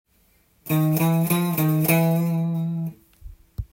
②のフレーズは３度の音に向かって弾き少しクロマチックスケールで
行き過ぎて、またコードトーンに戻るというフレーズ。